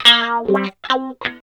74 GTR 1  -L.wav